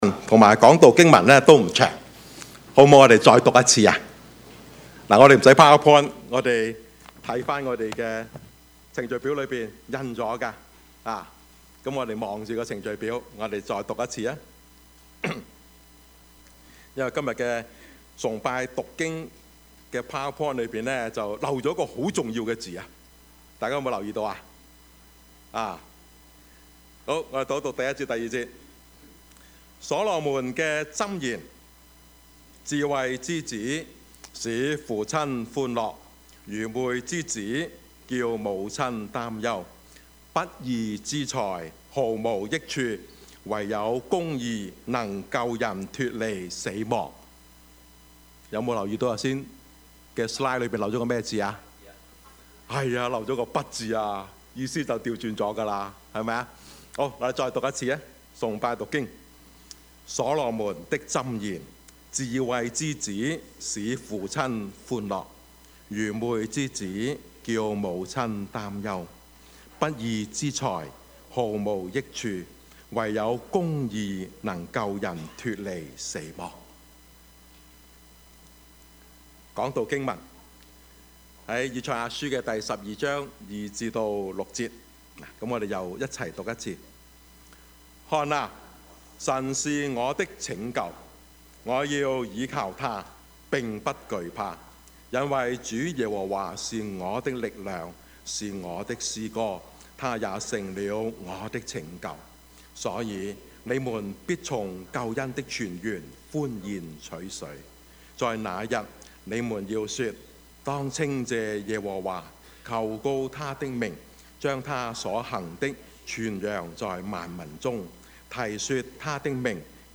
Service Type: 主日崇拜
Topics: 主日證道 « 比喻的信息: 活在主的恩典中 世界末日?